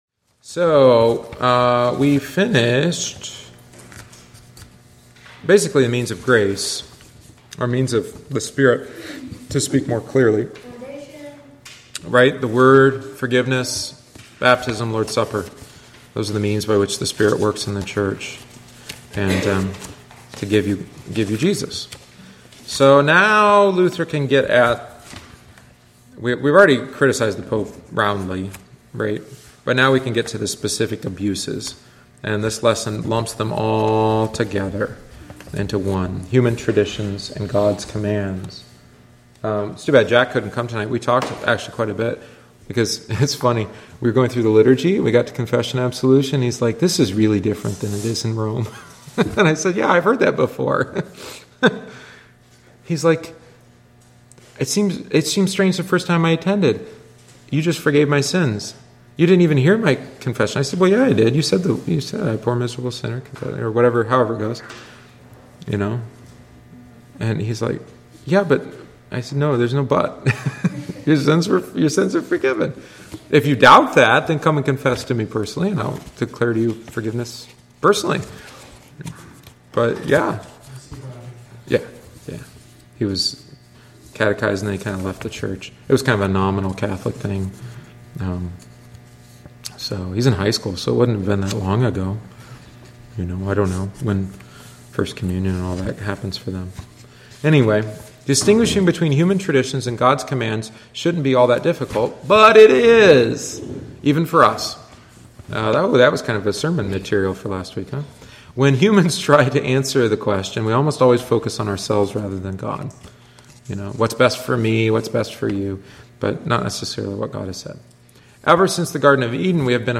Join us each week on Wednesday after Divine Service (~7:45pm) for aÂ study of one of our Lutheran Confessions, the Smalcald Articles. Written by Martin Luther shortly before his death, it is vivid, to the point, and sometimes cantankerous.